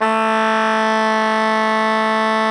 SOPRANO A 2.wav